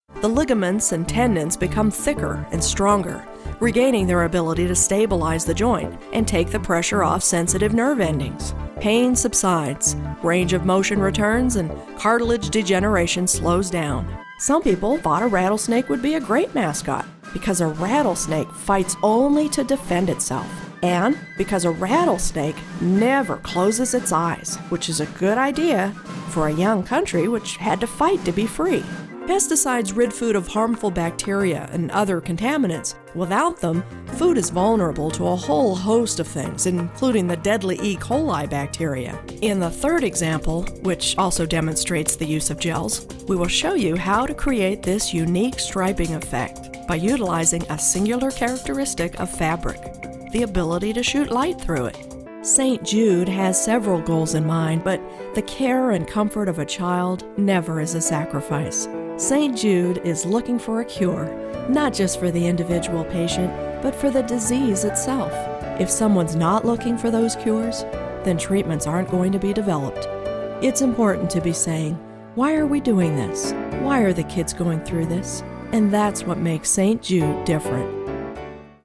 Conversational, Real Person, Sincere, Genuine, Expressive
middle west
Sprechprobe: Industrie (Muttersprache):